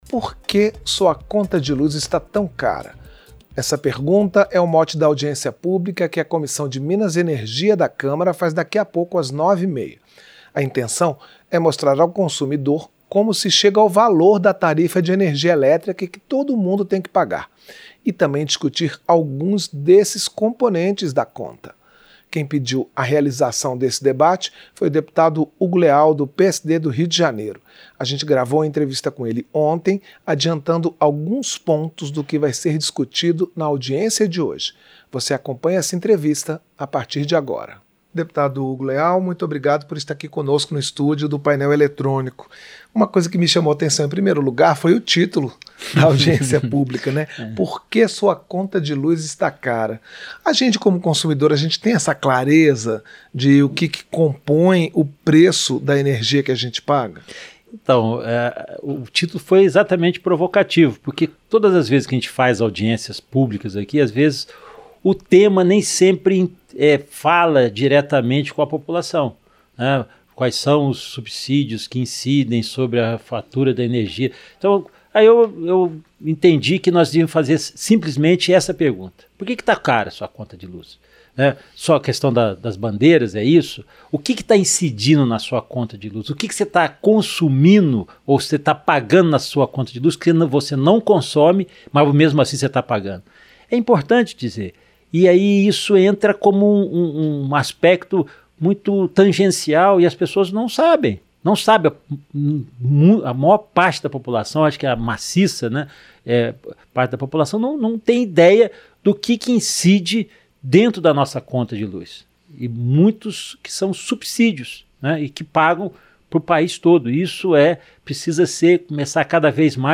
Entrevista - Dep. Hugo Leal (PSD-RJ)